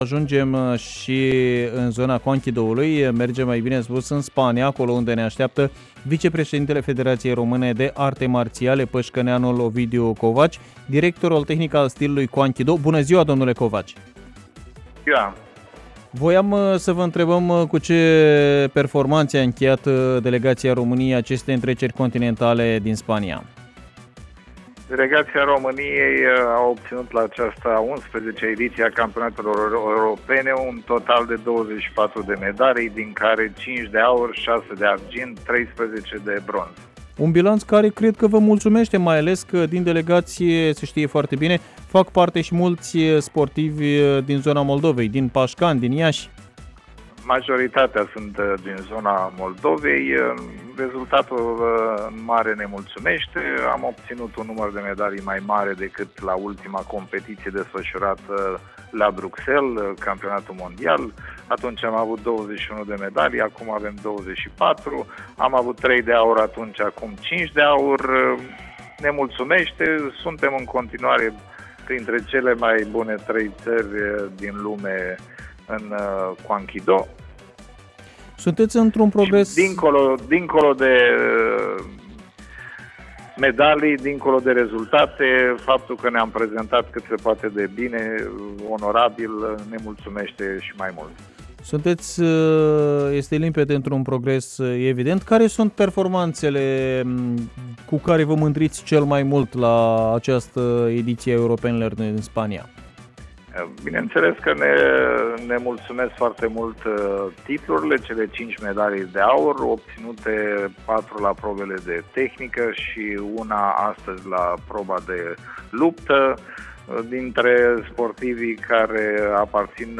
(INTERVIU) Performanță extraordinară pentru România! 24 de medalii la Europenele de Qwan Ki Do